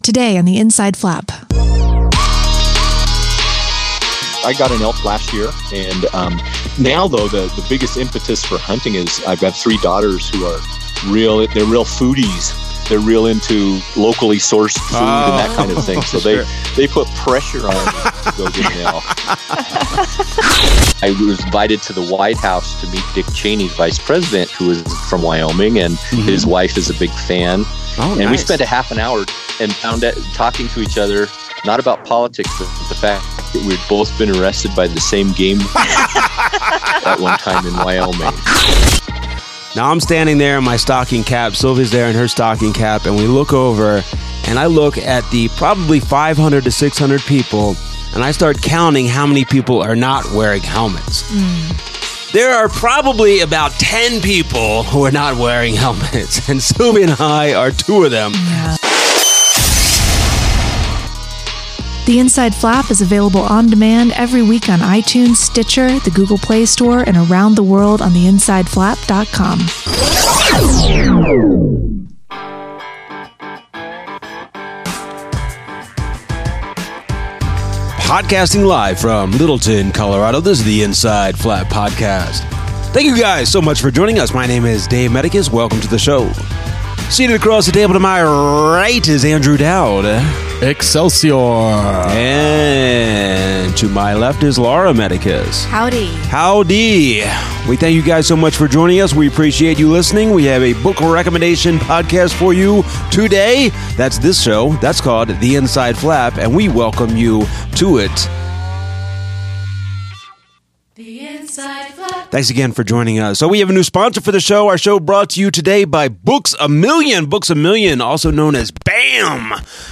Author C.J. Box joins us for a chat about his latest novel Long Range, getting pressured by his daughters to hunt elk, writing a series of books in real time, and the Denver Nuggets.